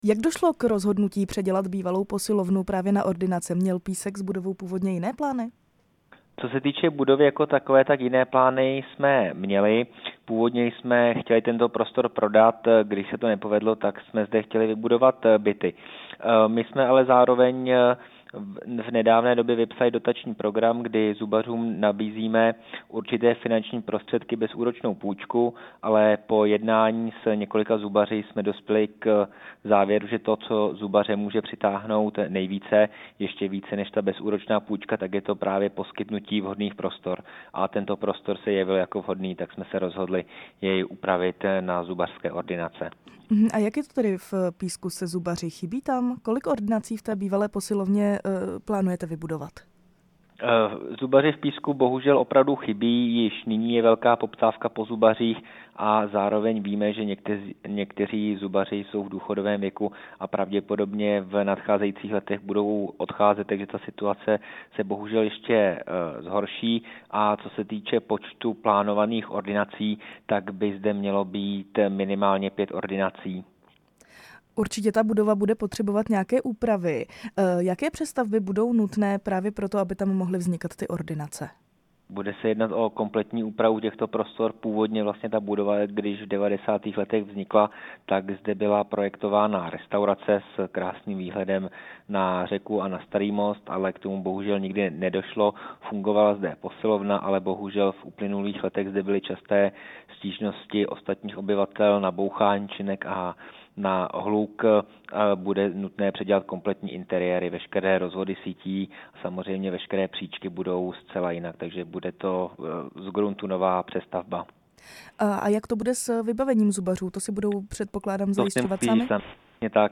Na to jsme se ve vysílání Rádia Prostor ptali starosty Písku Michala Čapka.
Rozhovor se starostou Písku Michalem Čapkem